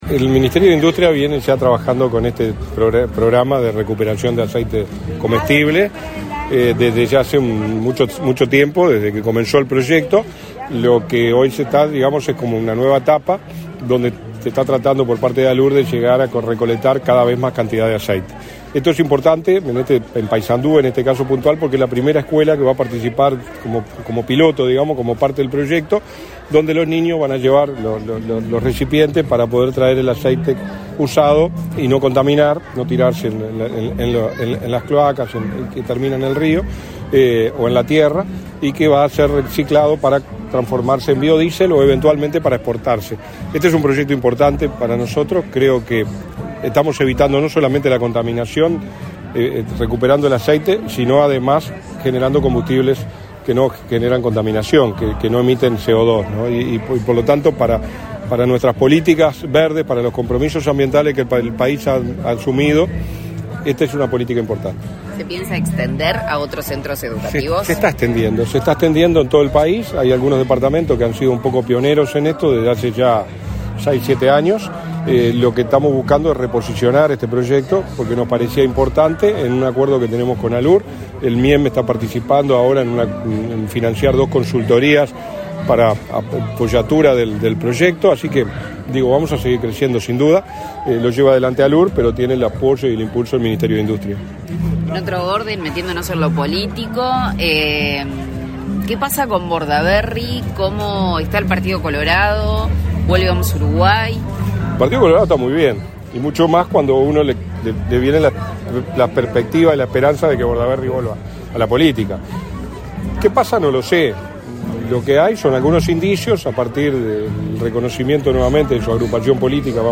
Declaraciones a la prensa del subsecretario de Industria, Energía y Minería, Walter Verri
Tras el evento, el subsecretario de Industria, Energía y Minería, Walter Verri, realizó declaraciones a la prensa.